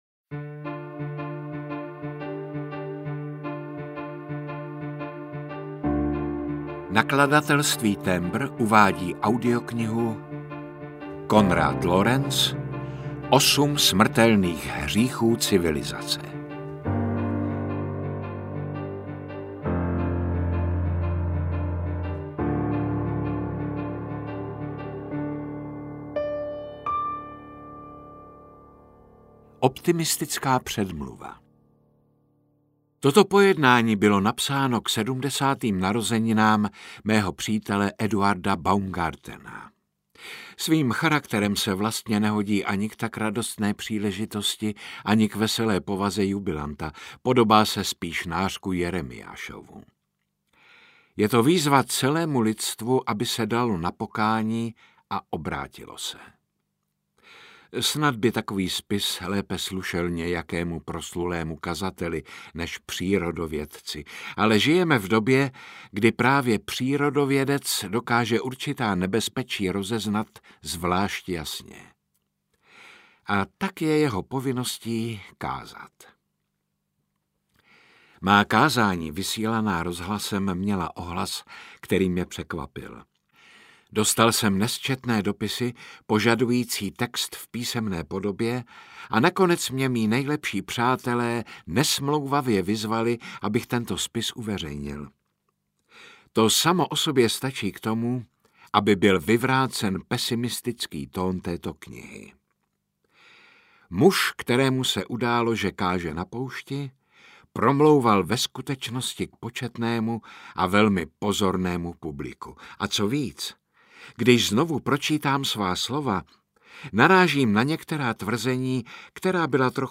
Osm smrtelných hříchů civilizace audiokniha
Ukázka z knihy
• InterpretJaromír Meduna